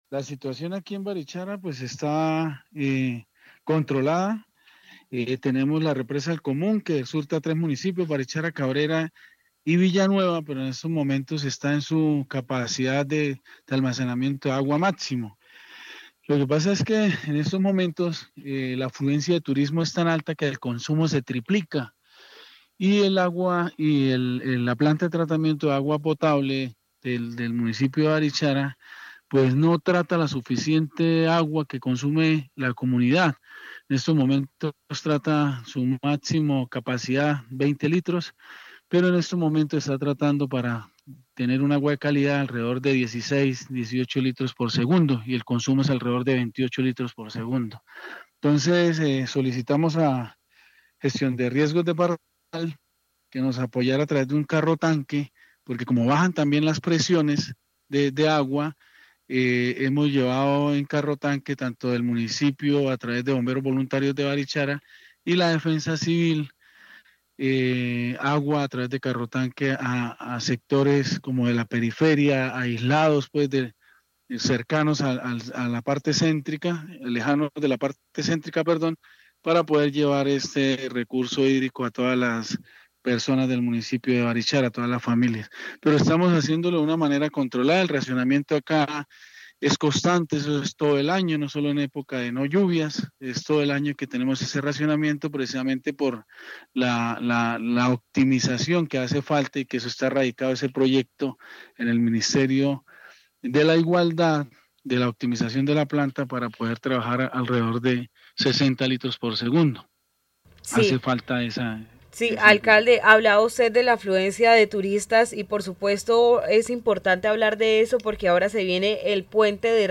Milton Chaparro, alcalde de barichara